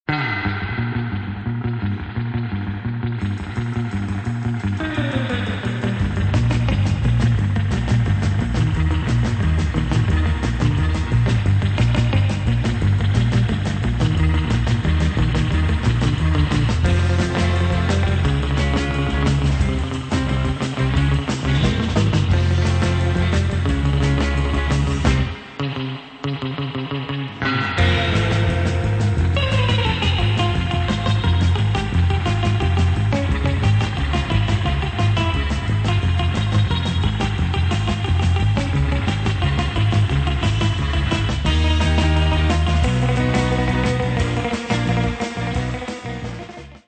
nervous medium instr.